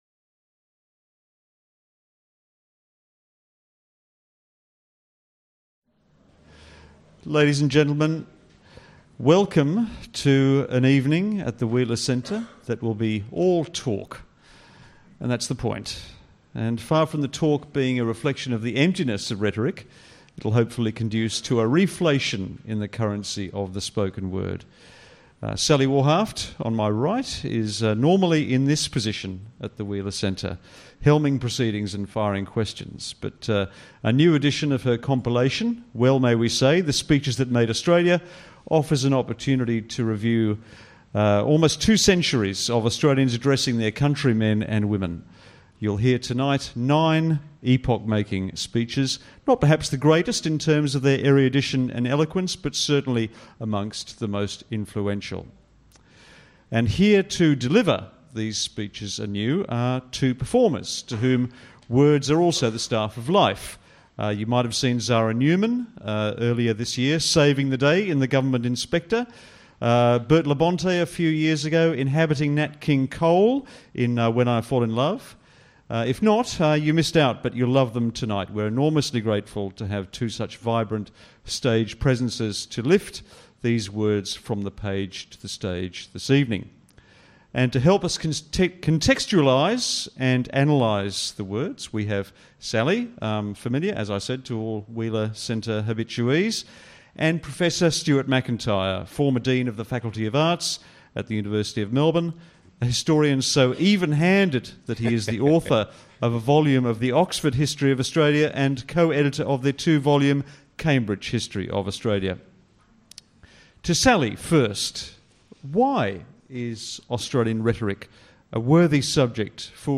Featured orations will reflect how these critical issues have evolved and changed with the times.